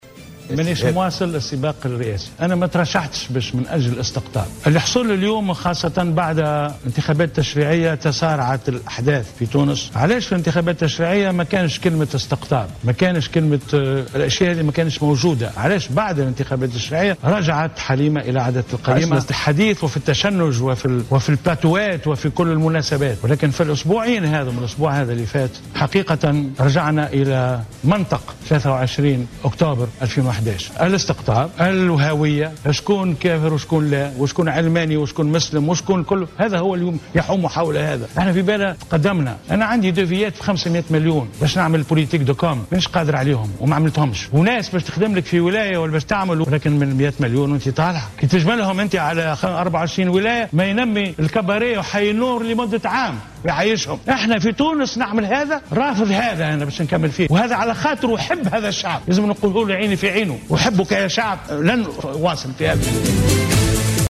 أعلن المرشح المستقل للانتخابات الرئاسية،نور الدين حشاد اليوم الاثنين 17 نوفمبر 2014 خلال حوار تلفزي على القناة الوطنية الأولى انسحابه من السباق الرئاسي.